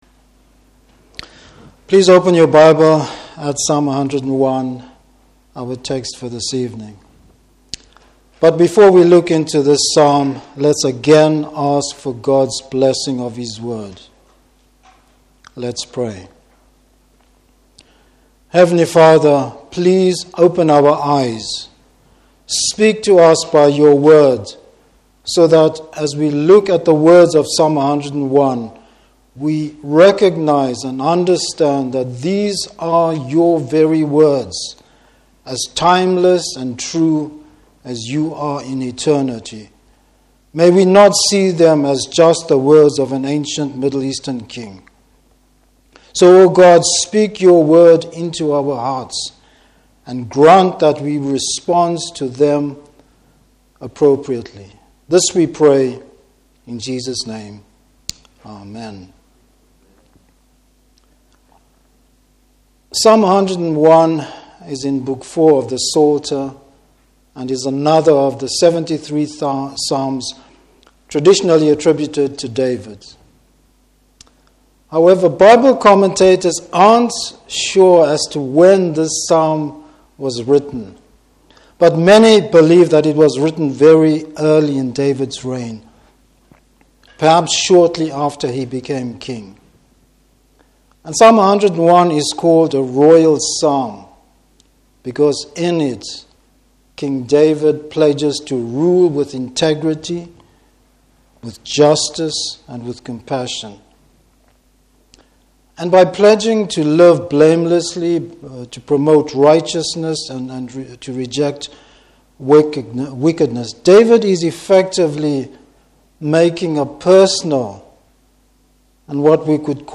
Series: New Years Sermon.
Service Type: Evening Service Living a life that pleases God.